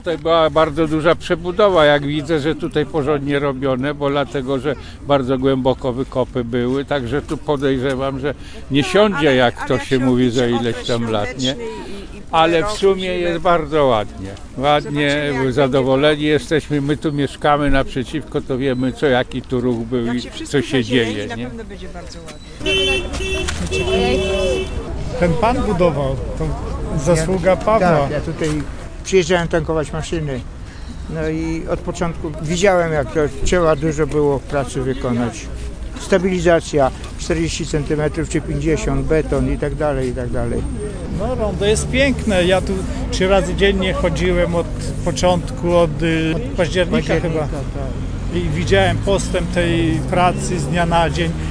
Posłuchaj wypowiedzi mieszkańców
0325_ludzie_o_rondzie.mp3